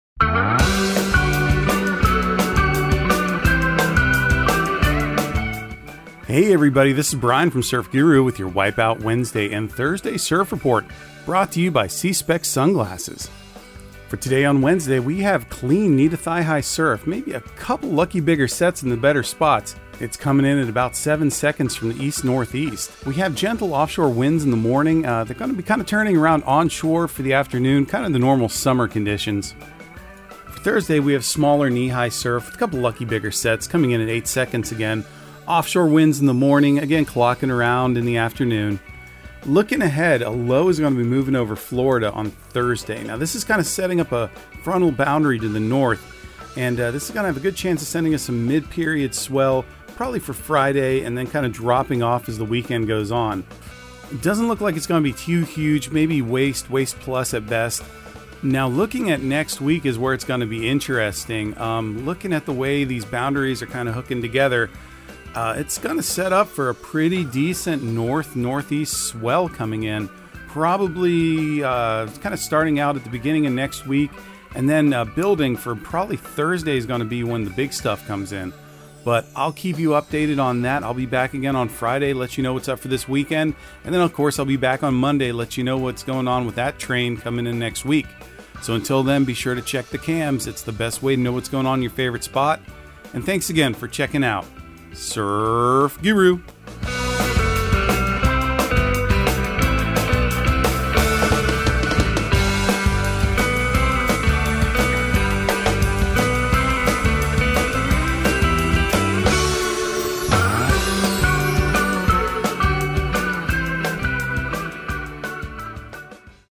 Surf Guru Surf Report and Forecast 05/17/2023 Audio surf report and surf forecast on May 17 for Central Florida and the Southeast.